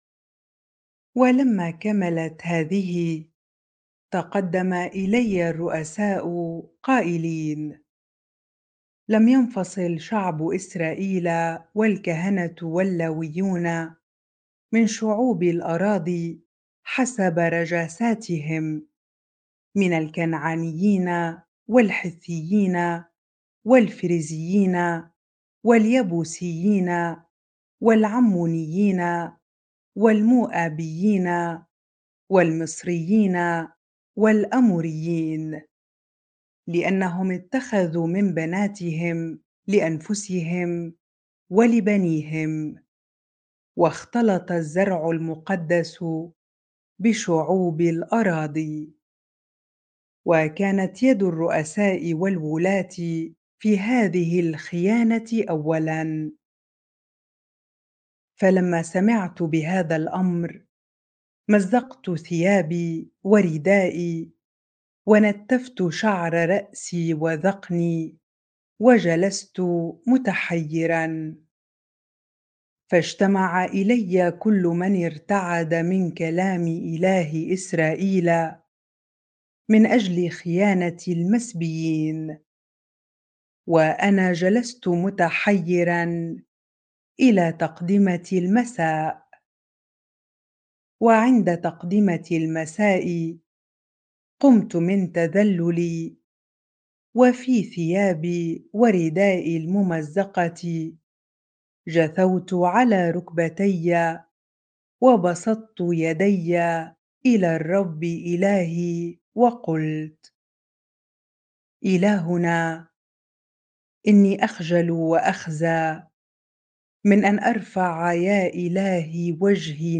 bible-reading-Ezra 9 ar